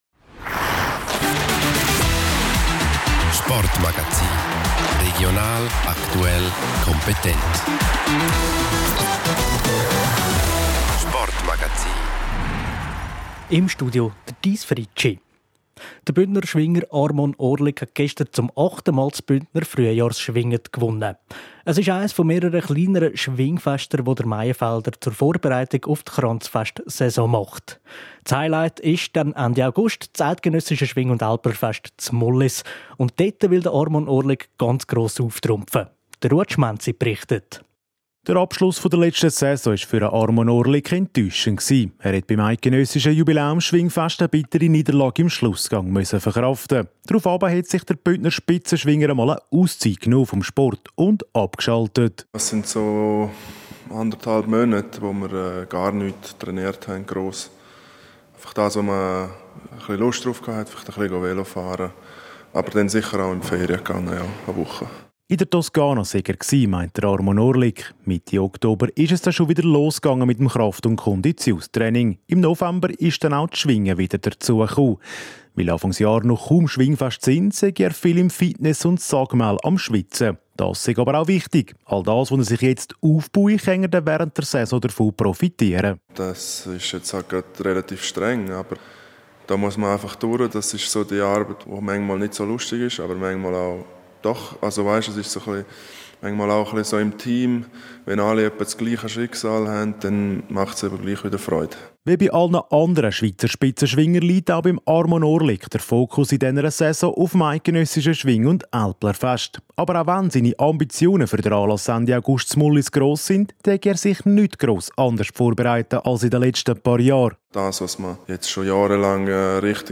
Sein Ziel ist klar: Er will sich in Mollis am ESAF zum Schwingerkönig krönen. Wie er das erreichen will, erzählt er im Sportmagagzin.